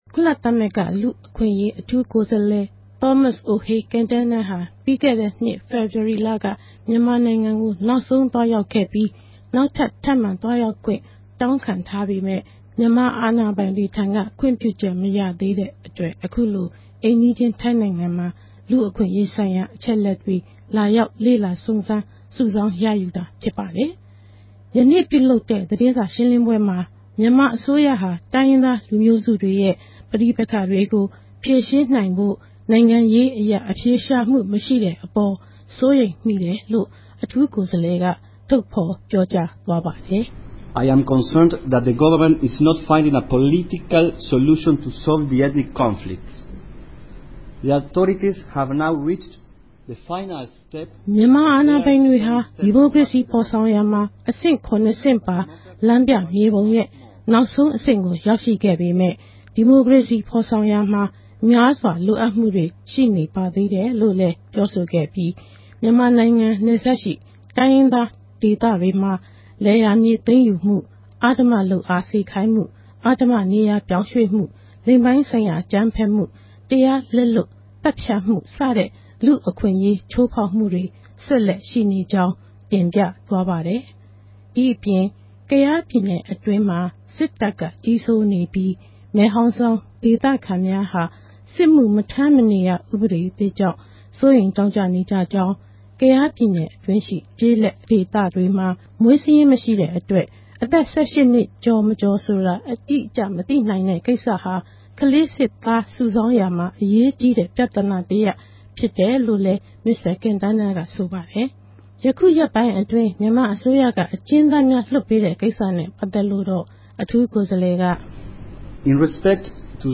မစ္စတာ ကင်တားနား၏ သတင်းစာရှင်းပွဲ